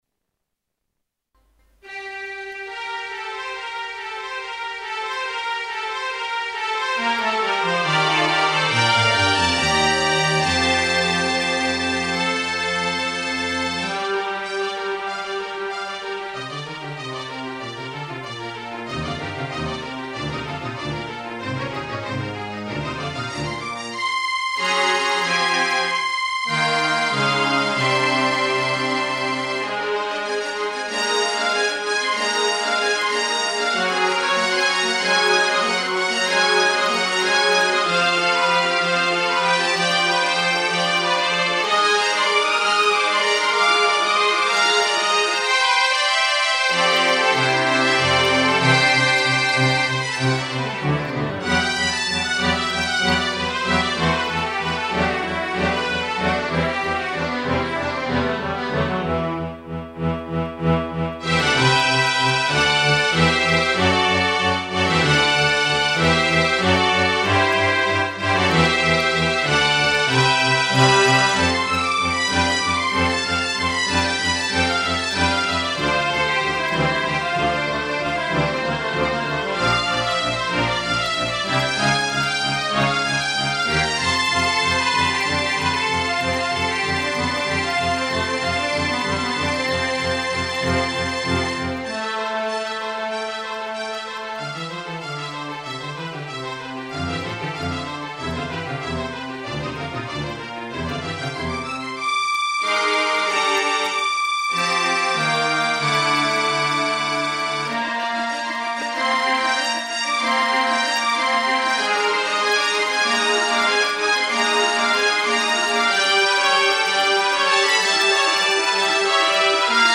kwartet.mp3.html